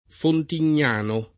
Fontignano [ fontin’n’ # no ] top. (Umbria)